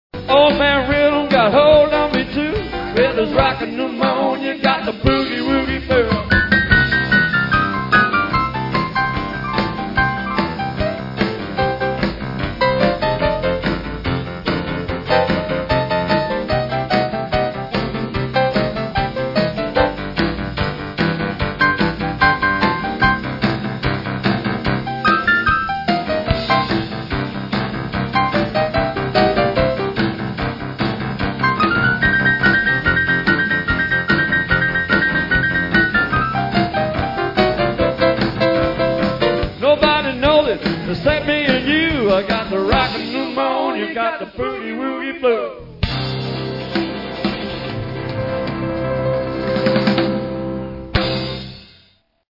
LIVE PERFORMANCE SECTION
STRAIGHT BOOGIE VERSION